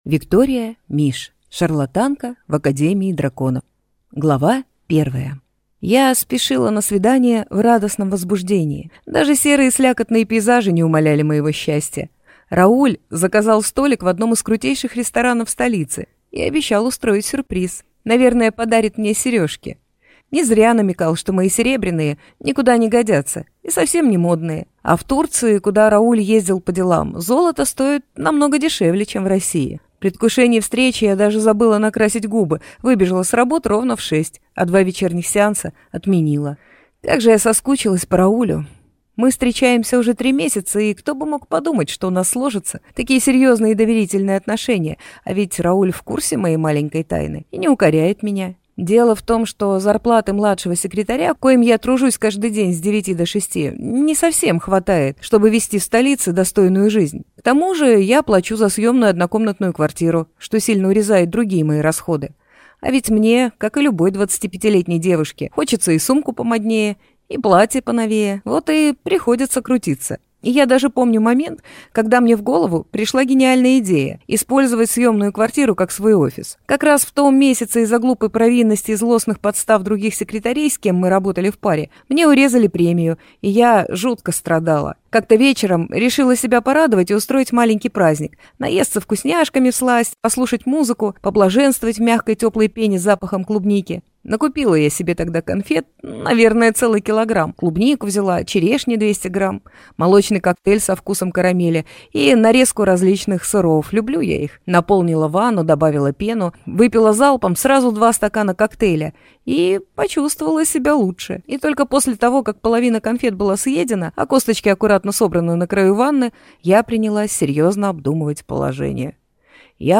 Аудиокнига Шарлатанка в Академии драконов | Библиотека аудиокниг